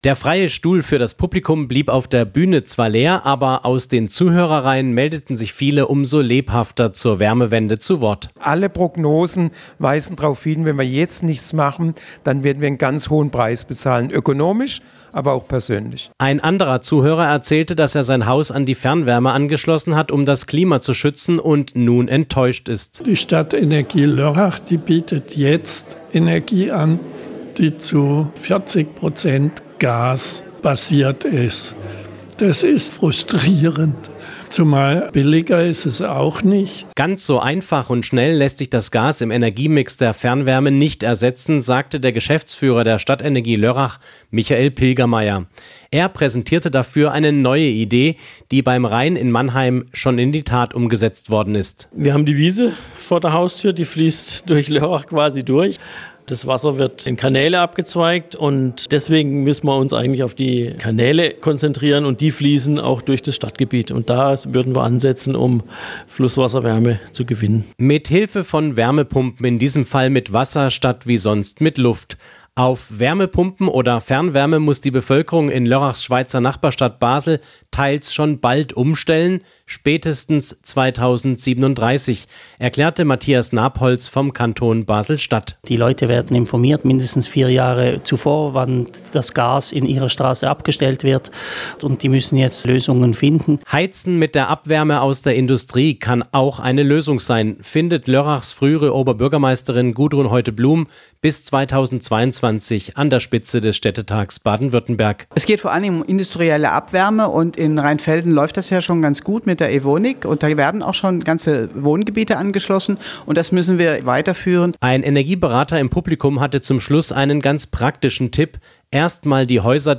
Am Mittwochabend ging es bei einer Diskussion um das Thema Gas bei der Energieversorgung.